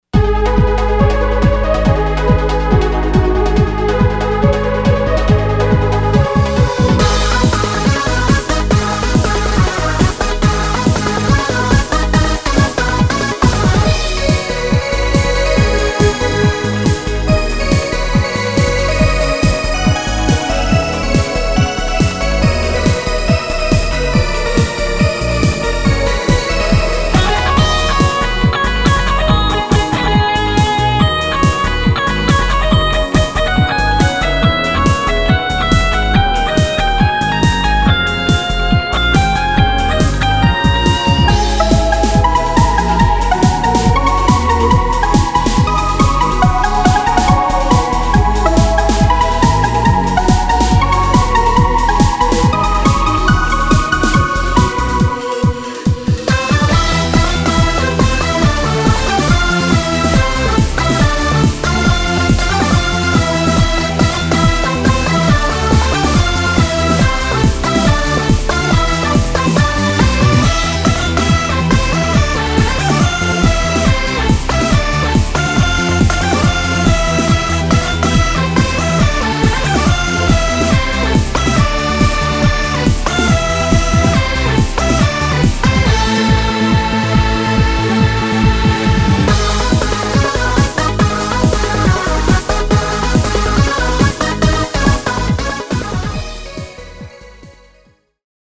フリーBGM イベントシーン 熱い・高揚
フェードアウト版のmp3を、こちらのページにて無料で配布しています。